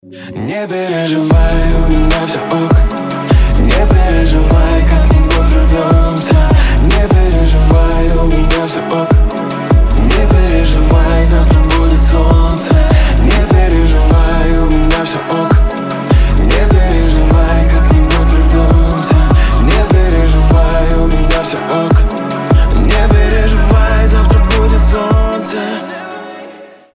• Качество: 320, Stereo
дуэт